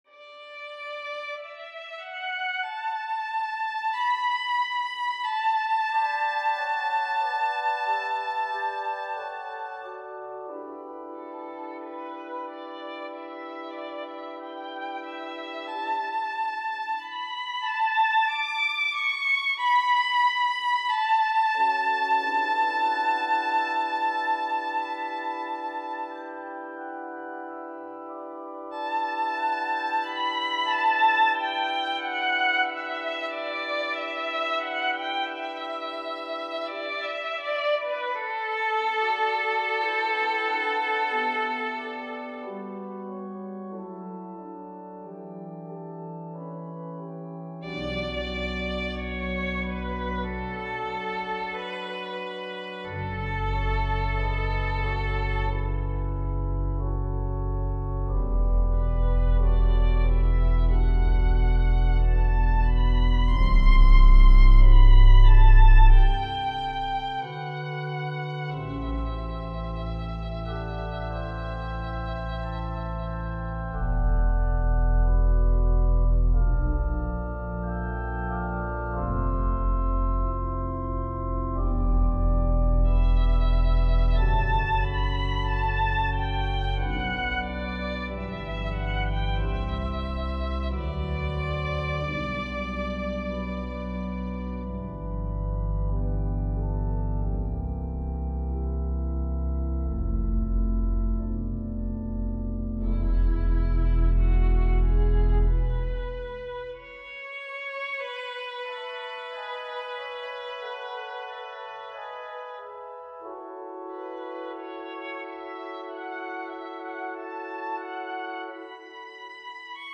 Voicing: Inst w/Org